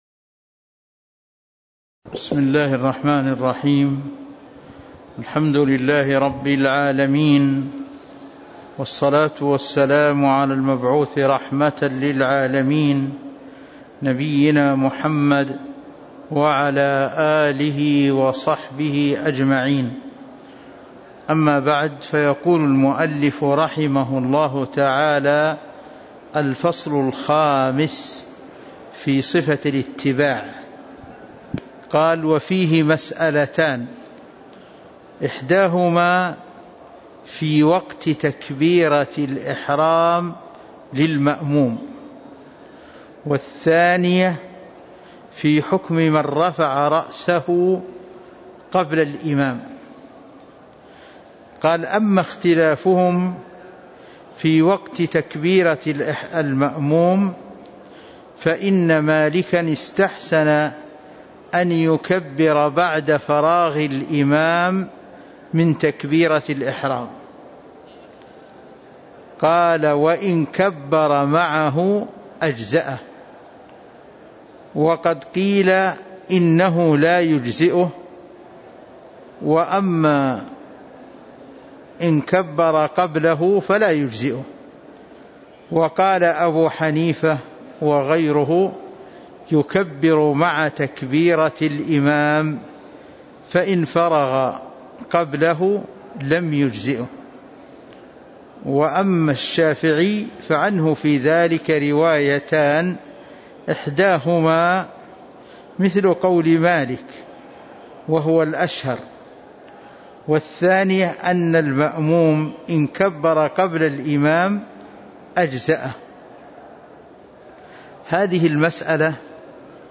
تاريخ النشر ٩ ربيع الثاني ١٤٤٣ هـ المكان: المسجد النبوي الشيخ